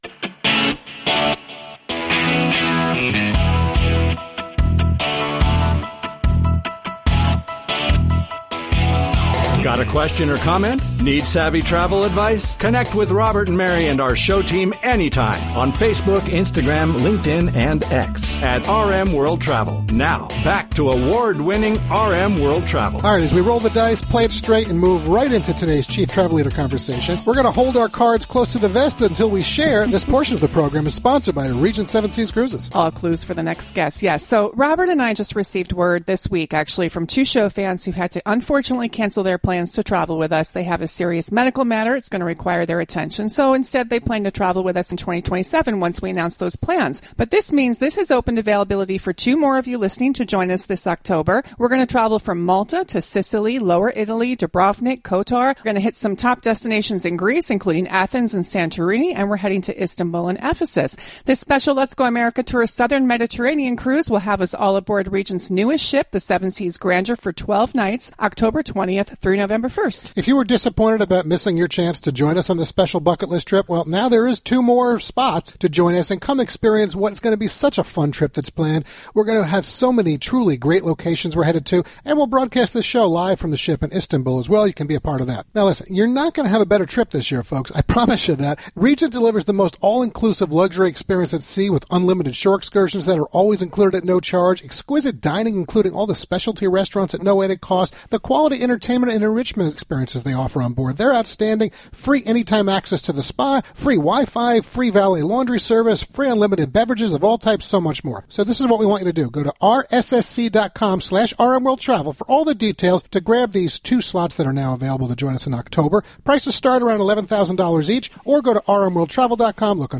During the live national broadcast of America’s #1 Travel Radio Show on March 14th 2026, we connected with him for the latest “Chief Travel Leader” conversation